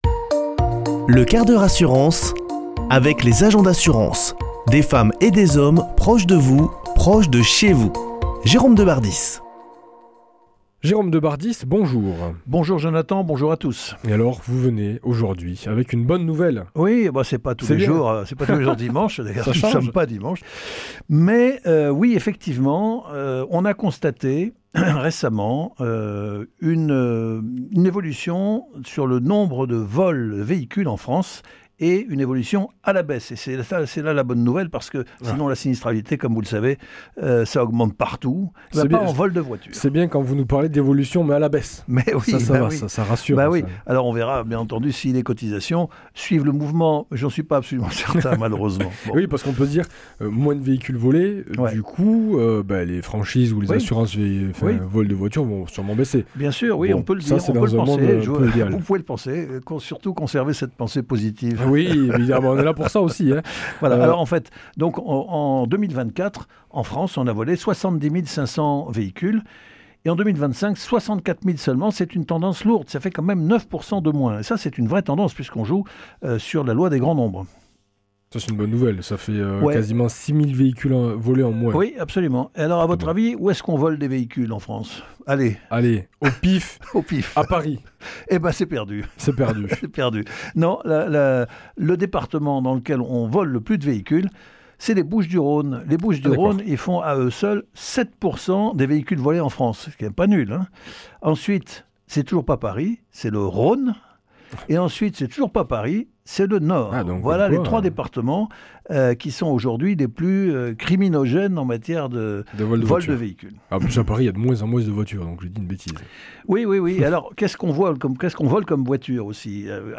mardi 14 avril 2026 Chronique le 1/4 h assurance Durée 5 min
Chroniqueur